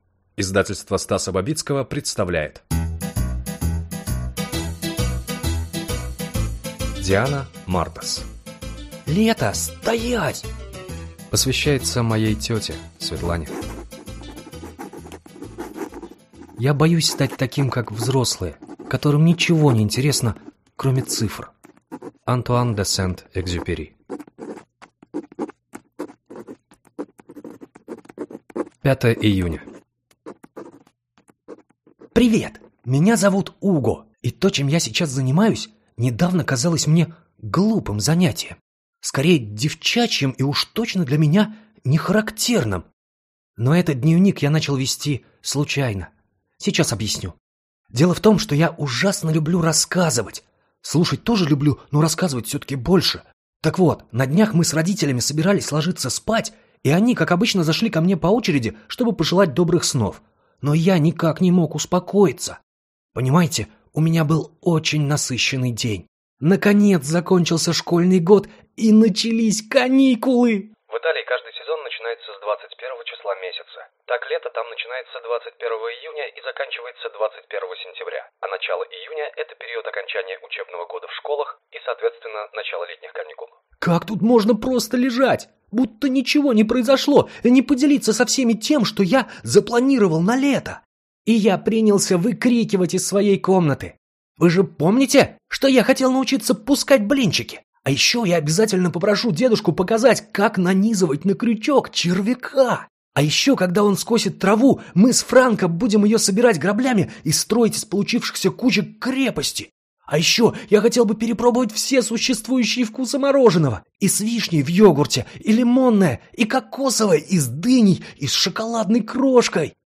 Аудиокнига Лето, стоять!